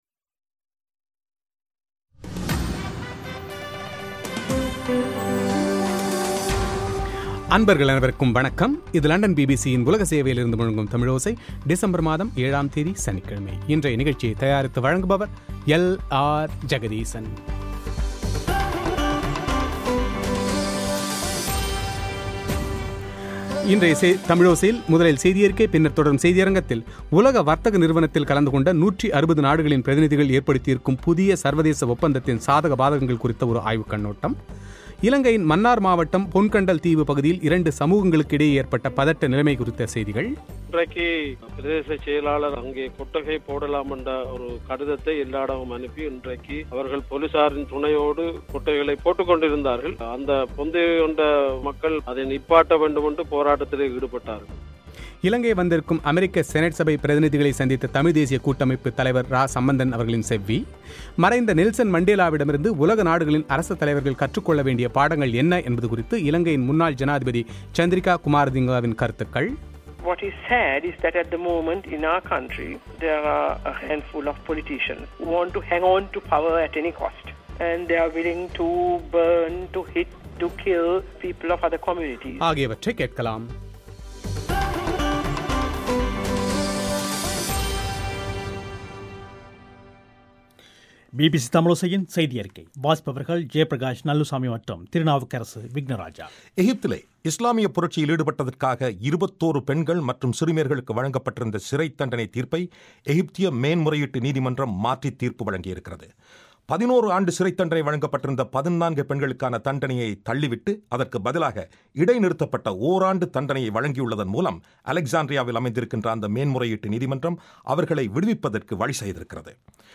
தமிழ்த் தேசியக் கூட்டமைப்புத் தலைவர் சம்பந்தன் அவர்களின் பேட்டி